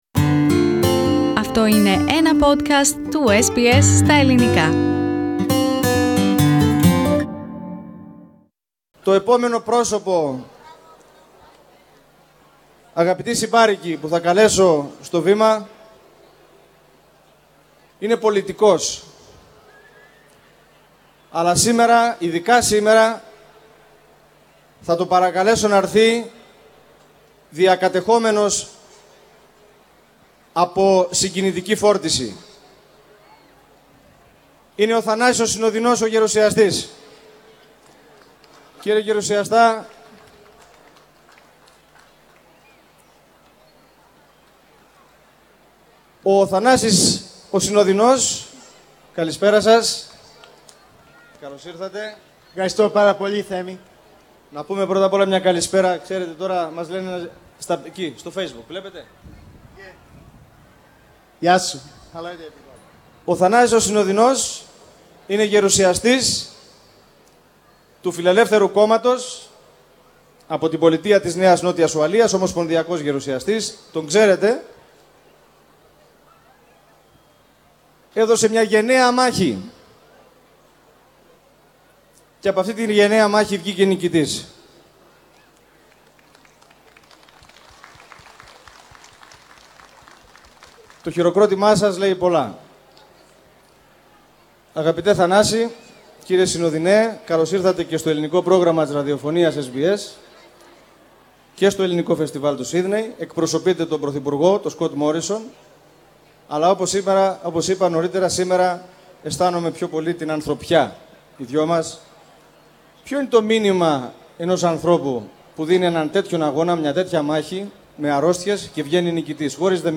After his health adventure Liberal Senator for NSW Athanasios (Arthur) Synodinos, was present at the 37th Greek Festival and Sydney and spoke to SBS Greek.